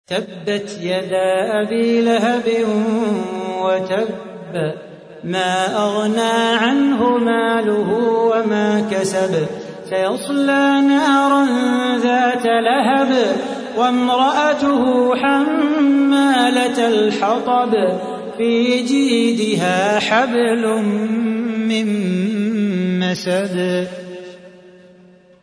تحميل : 111. سورة المسد / القارئ صلاح بو خاطر / القرآن الكريم / موقع يا حسين